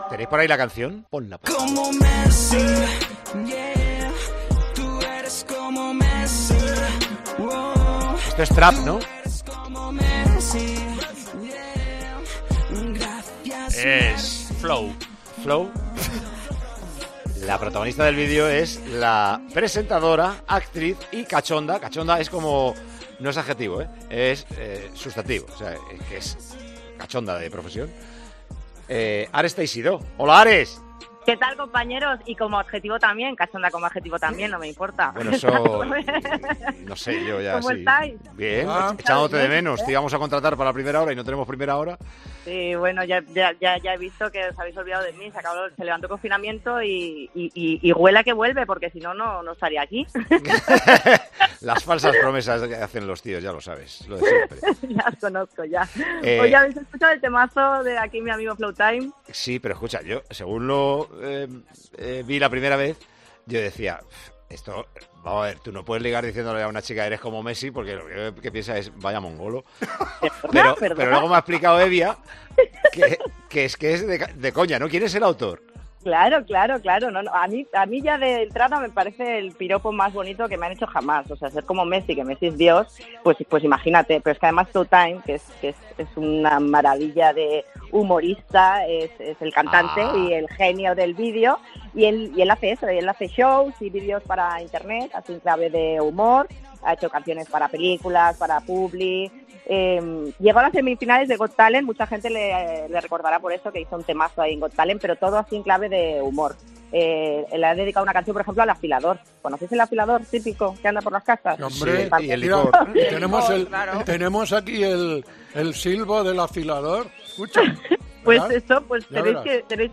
AUDIO: Hablamos con la presentadora tras su aparición en el videolclip "Ella es como Messi" de Flowtime.